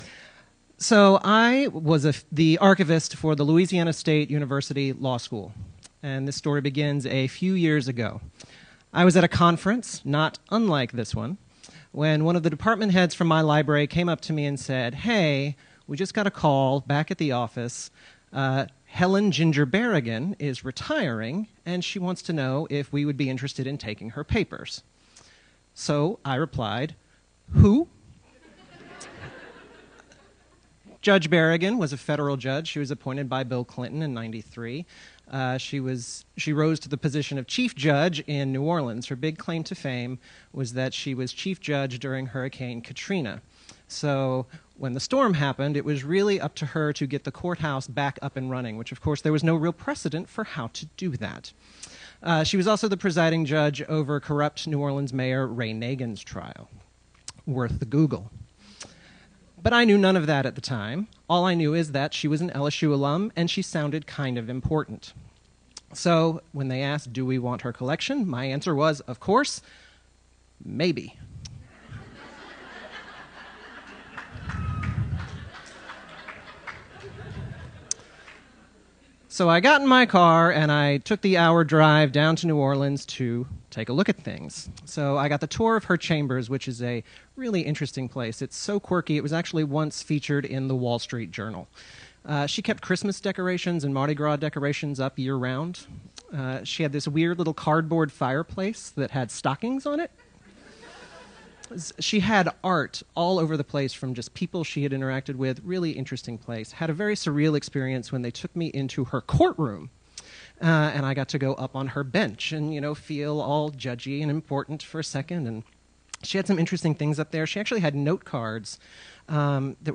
This annual storytelling event brings together archivists for an evening of stories that energize and inspire, and celebrate the diversity of the archivist experience.